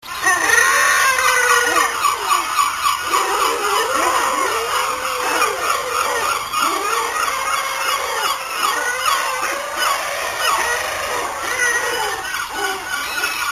safari.mp3